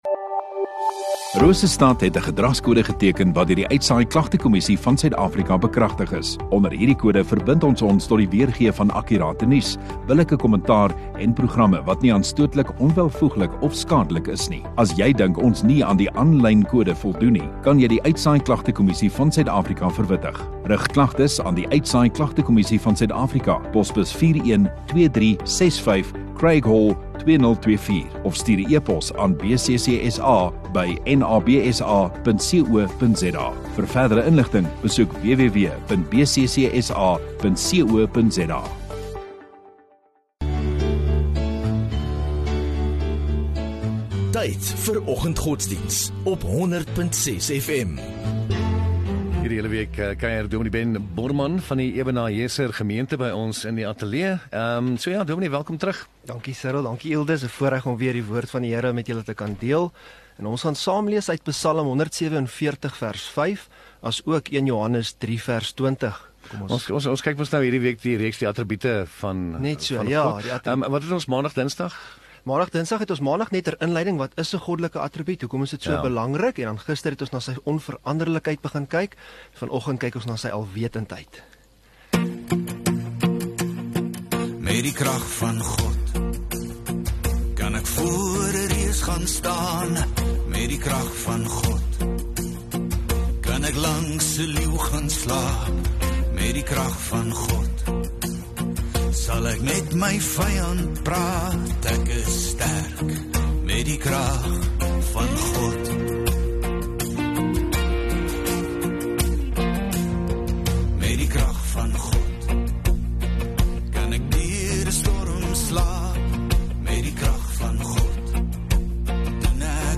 22 May Woensdag Oggenddiens